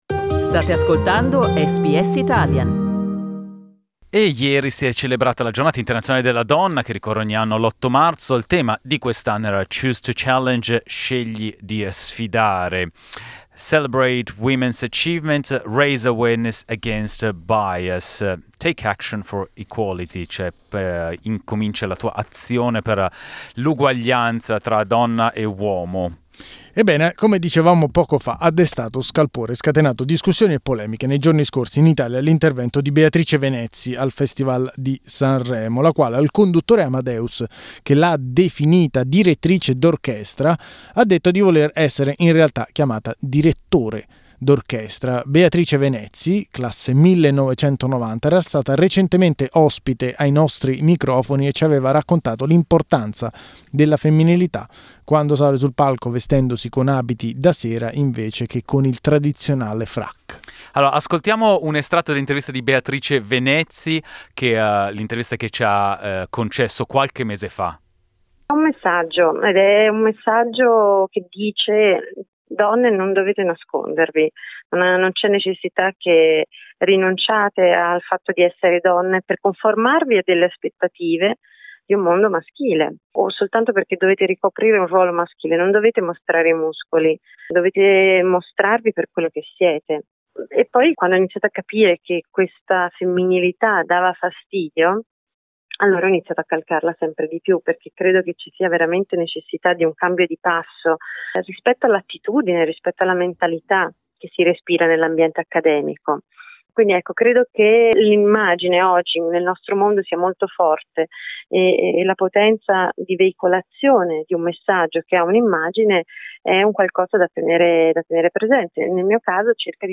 abbiamo parlato con due ospiti